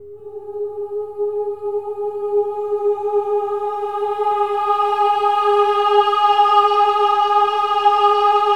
OH-AH  G#4-L.wav